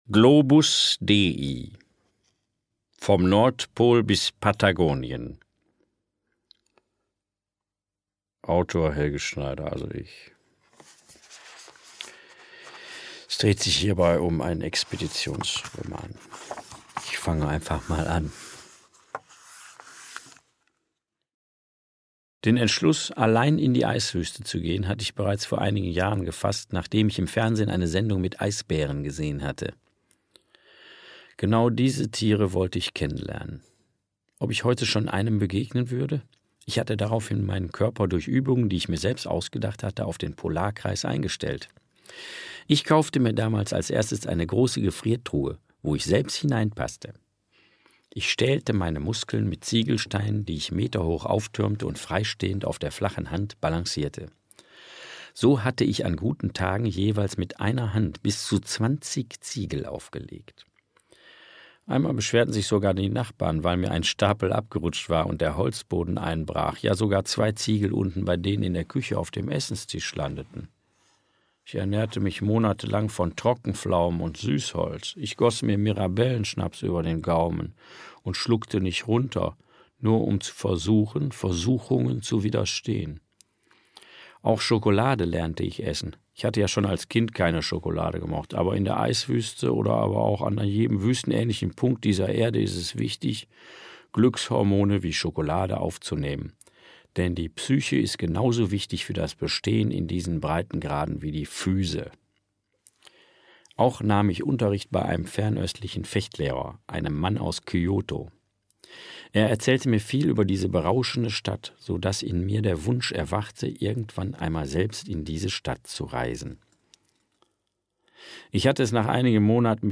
Helge Schneider (Sprecher)
2021 | 1. Auflage, Ungekürzte Ausgabe